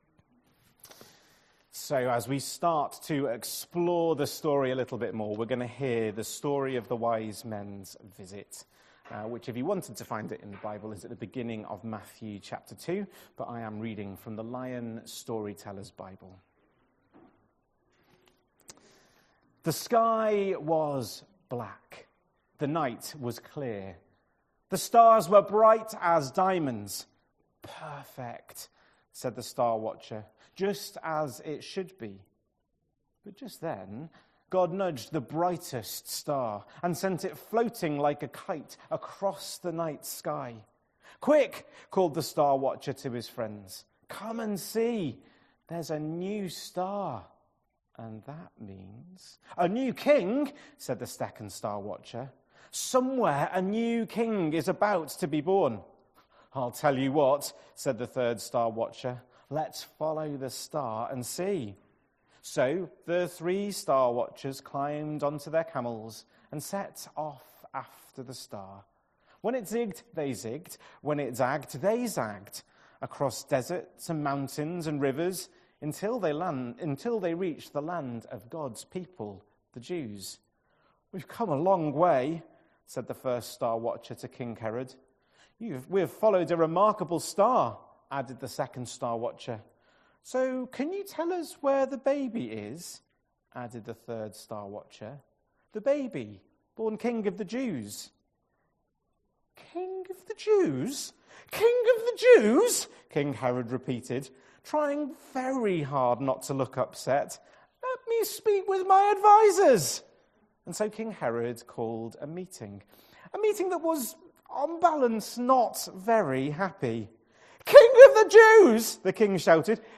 4th January 2026 Sunday Reading and Talk - St Luke's